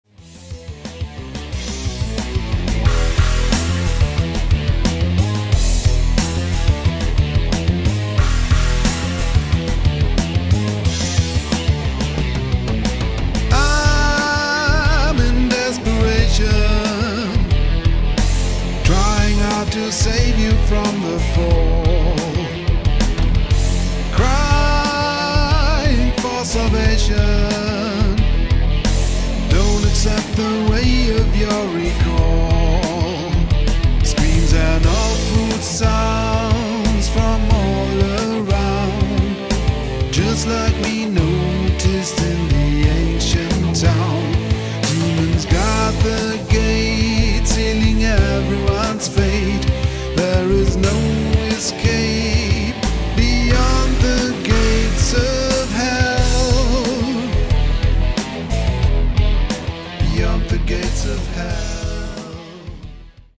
Der orientalisch anmutende Titelsong
ist sehr rockig und weist Einflüsse auf